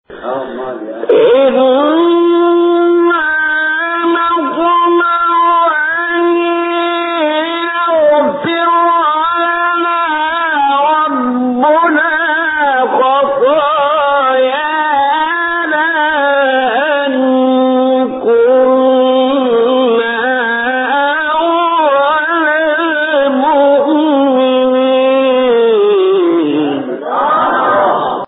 به گزارش خبرگزاری بین المللی قرآن(ایکنا) هشت فراز صوتی از محمد محمود رمضان، قاری برجسته مصری در کانال تلگرامی قاریان مصری منتشر شده است.
این فرازها از تلاوت سوره شعراء است که در مقام‌های بیات، کرد، صبا، حجاز، نهاوند، رست، چهارگاه و سه‌گاه اجرا شده است.
مقام بیات